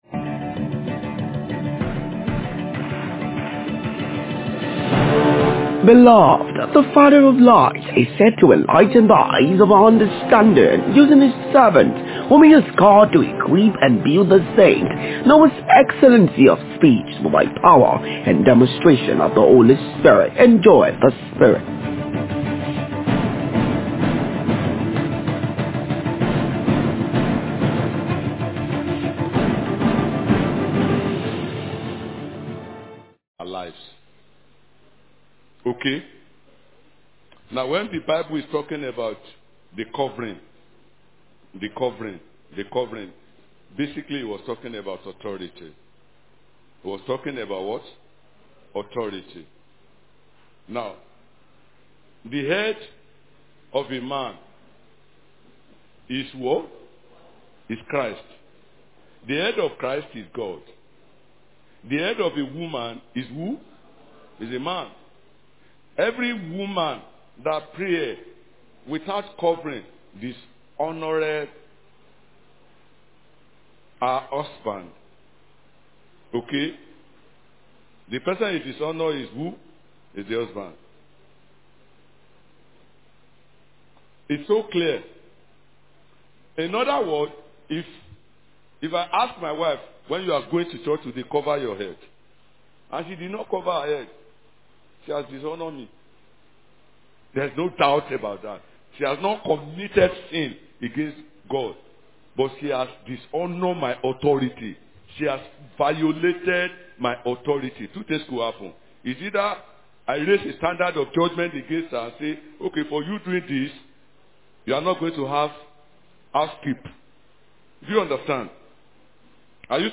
DAY-6-QUESTION-AND-ANSWERS-SESSION.mp3